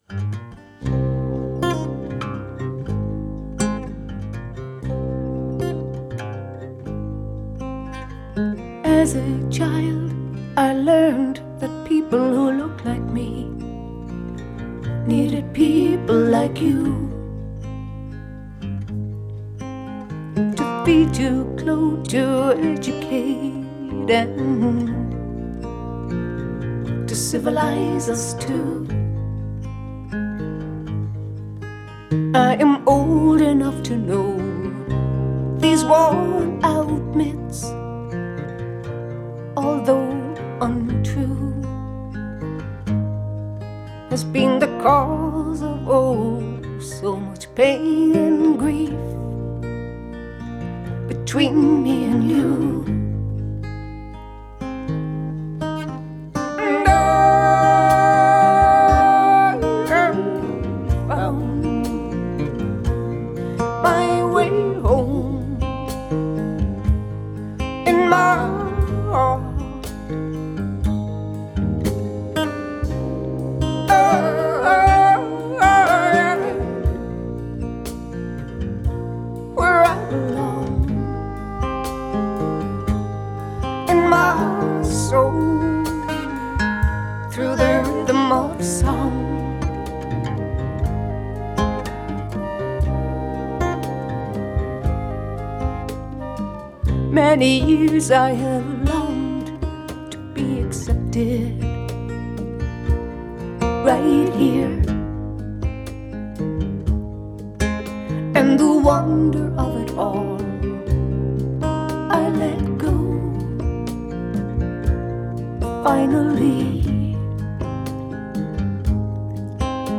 Genre: Folk/Rock